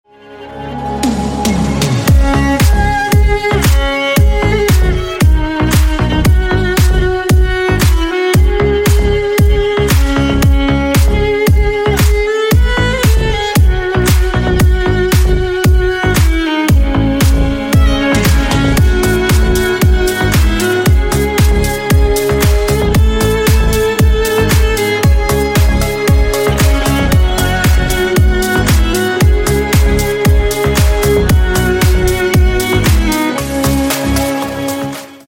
Рингтоны Без Слов
Танцевальные Рингтоны